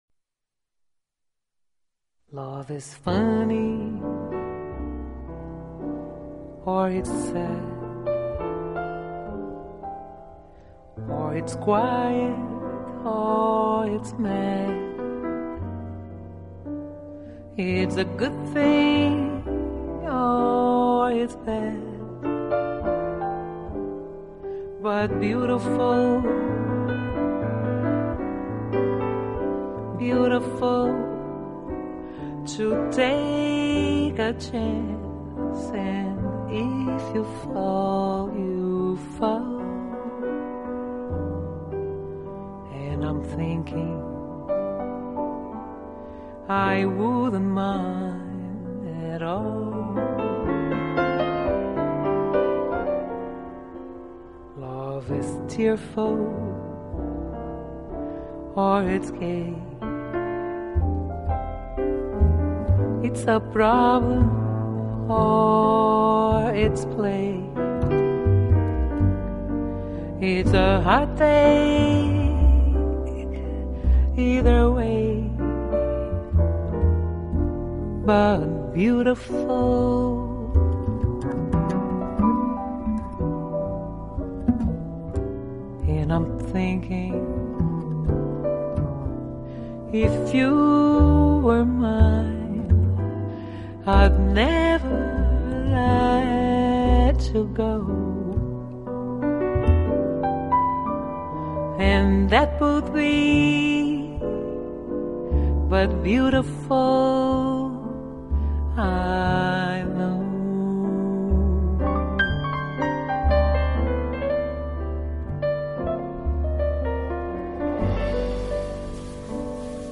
【爵士钢琴】